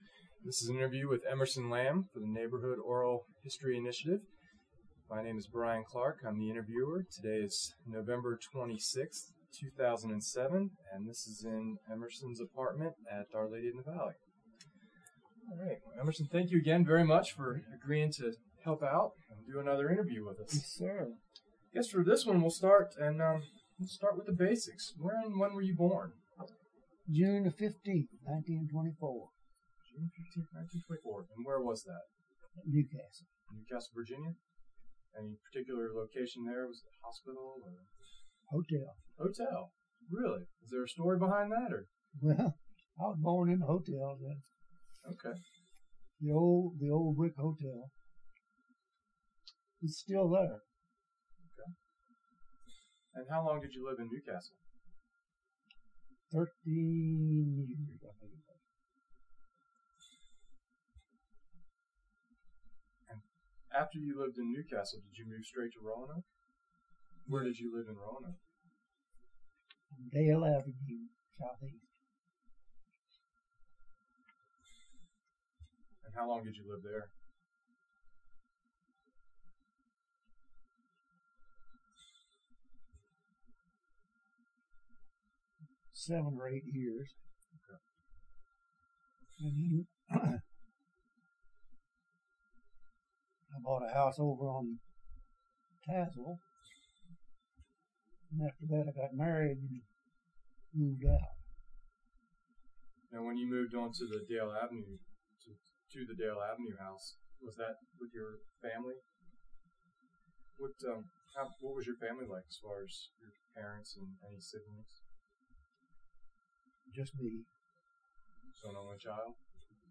Neighborhood History Interview
Location: Our Lady of the Valley